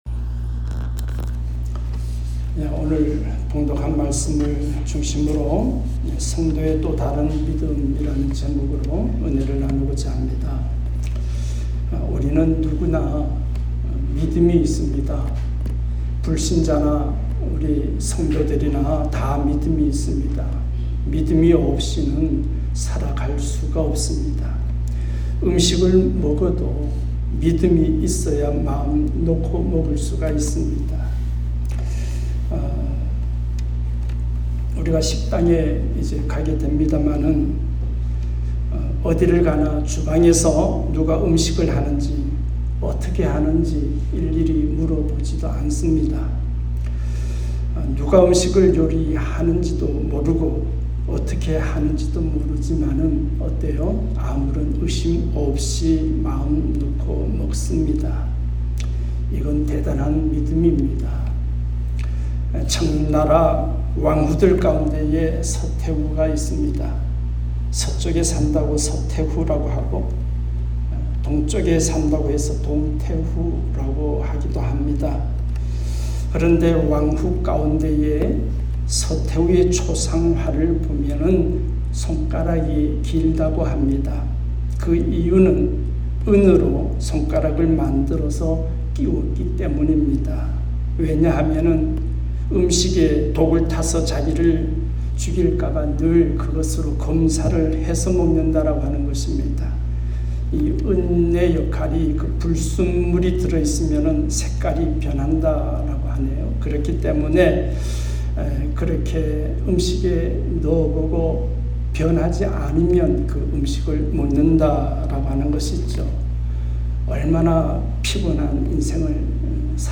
성도의 또 다른 믿음 ( 눅7:36-50 ) 말씀